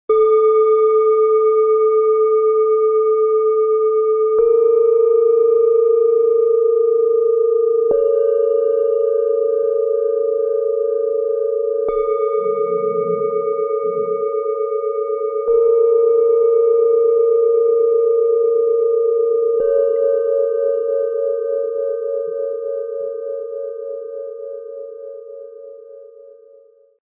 Stillpunkt - Zentrierung im Klang - Set aus 3 Klangschalen in schwarz-gold, Ø 11,1 - 12,7 cm, 1,17 kg
Der tiefste Ton bringt Halt und Ruhe in den Bauchbereich.
Der höchste Ton klingt sanft aufsteigend und hilft, die Aufmerksamkeit ins Zentrum zu führen.Gemeinsam schaffen die Schalen ein fein abgestimmtes Klangbild, das Schritt für Schritt zur Ruhe führt - wie ein Weg von außen nach innen, getragen vom Ton.
Ihr warmer, tragender Klang gibt Sicherheit und Ruhe.
Die mittlere Schale klingt zentriert, offen und klar.
Mit feinem, hellen Ton führt sie die Aufmerksamkeit nach innen - ruhig, licht und fokussierend.
Das Set entfaltet einen sanften, zentrierenden Klangbogen - von tiefer Erdung bis zur feinen Mitte.
MaterialBronze